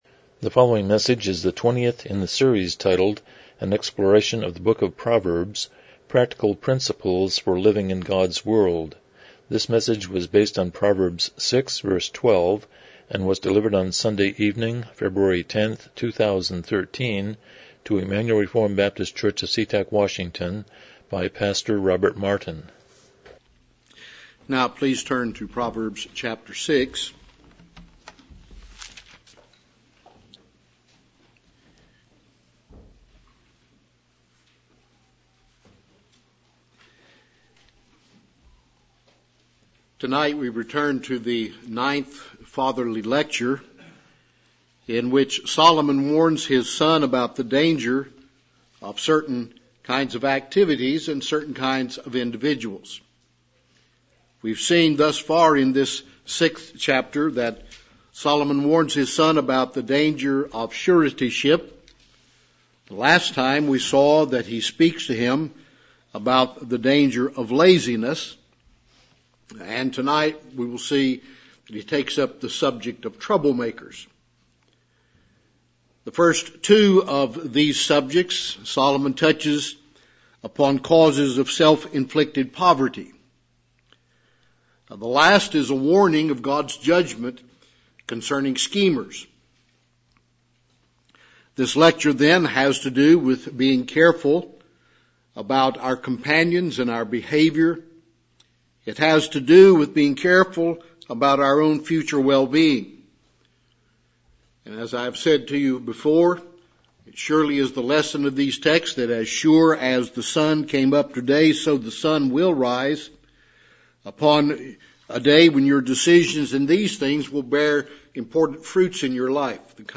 Proverbs 6:12 Service Type: Evening Worship « 24 The Sermon on the Mount